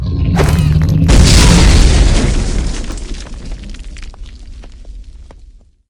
grenade2.ogg